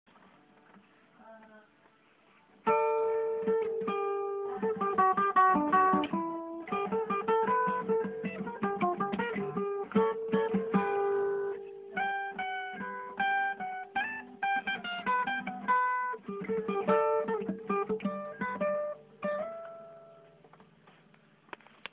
на акустике